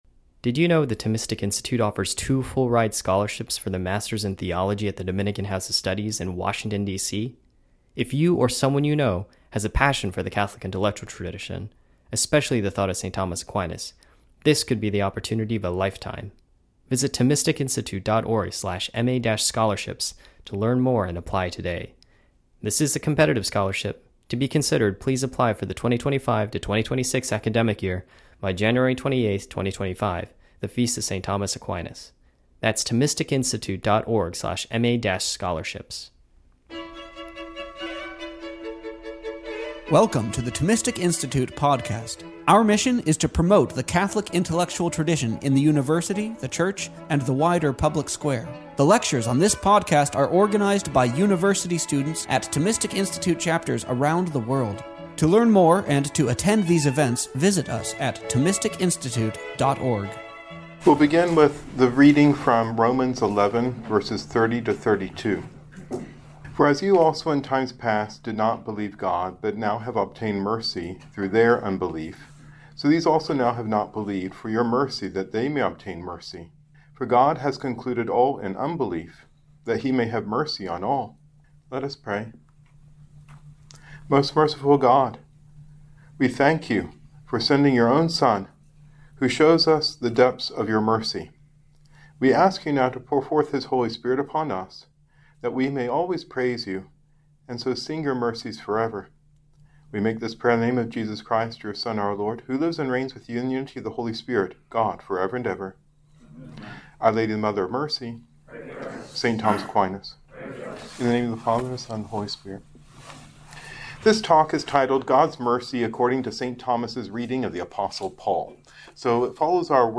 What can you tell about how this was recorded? This lecture was given on October 19th, 2024, at Dominican House of Studies.